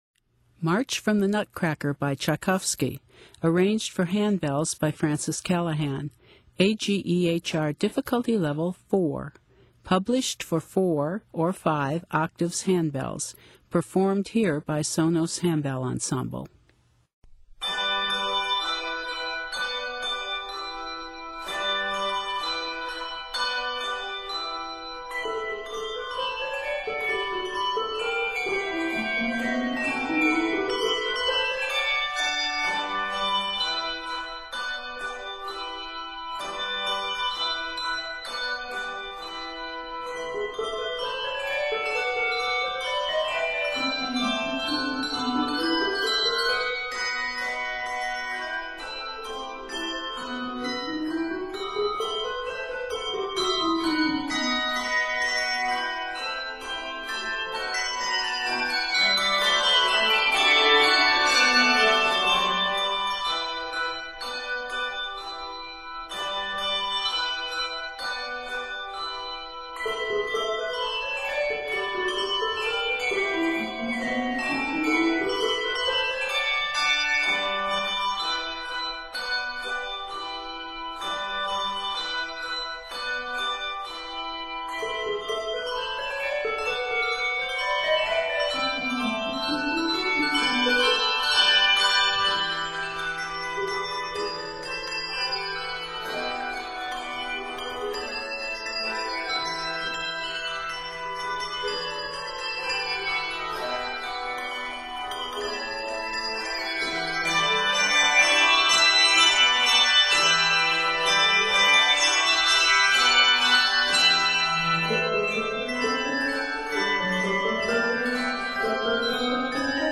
comes to life on handbells
Octaves: 4-5